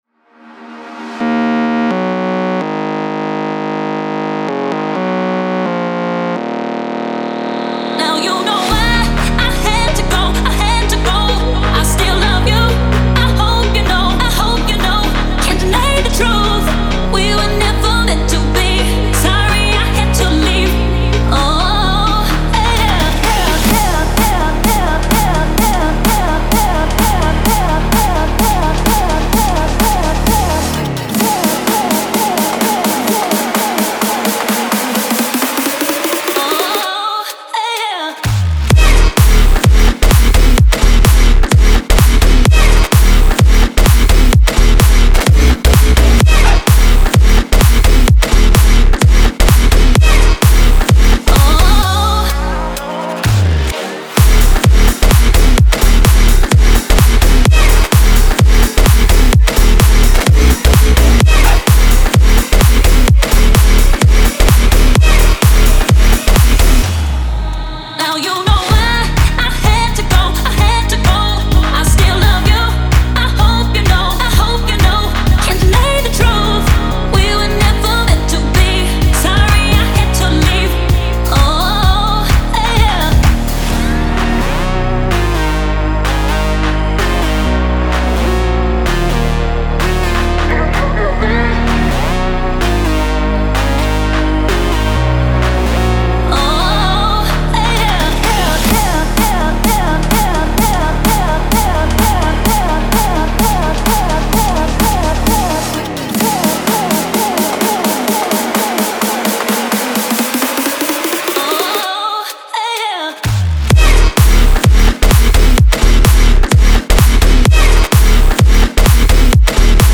Future House, Epic, Euphoric, Energetic, Gloomy, Dark